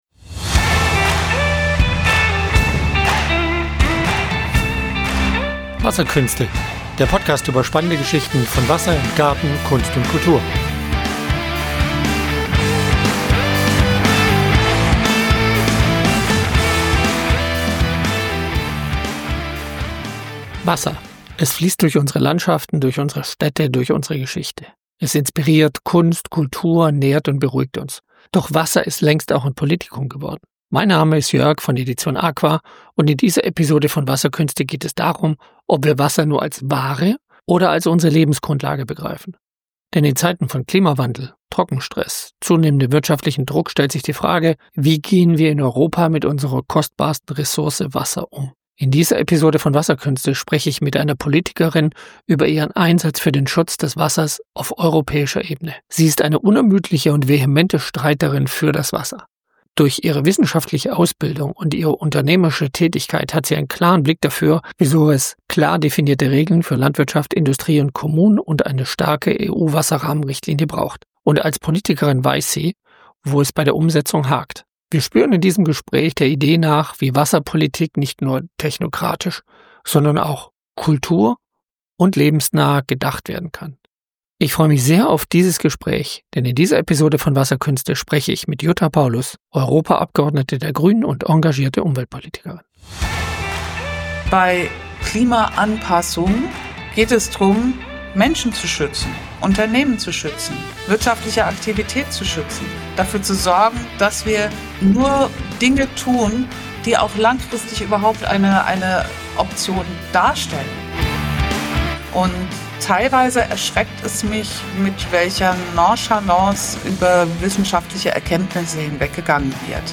Wenn Wasser den politischen Alltag bestimmt - Interview mit Jutta Paulus (MdEP) ~ Wasserkünste - Podcast über Natur, Reisen, Kunst und Kultur Podcast